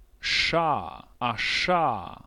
44 ʂ consonant fricative retroflex unvoiced [
voiceless_retroflex_fricative.wav